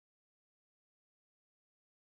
01. silence-A - silence-A.mp3